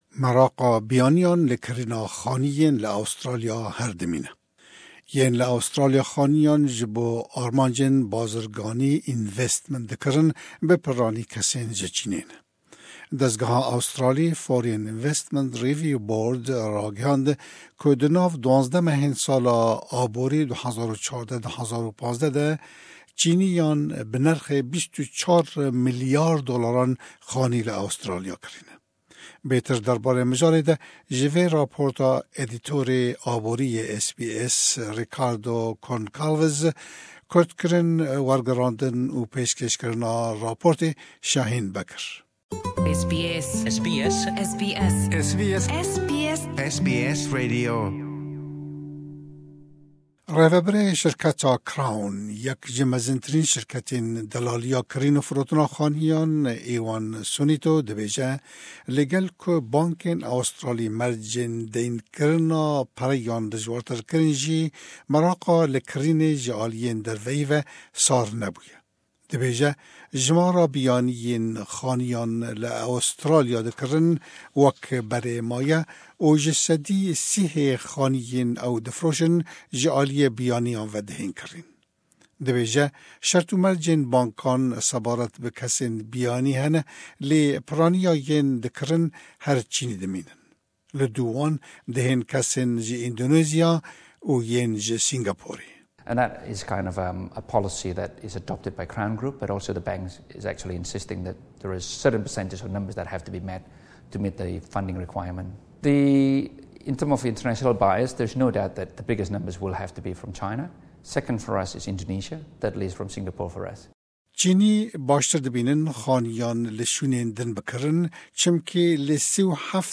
Jimara kesên biyanî yên xaniyan li Australya dikirine her wek xwe dimîne. Bi taybetî chinî salê bi milyaran dollar ji bo kirîna xaniyên li Australya bi kar dihînin. Bêtir ji vê raporta me.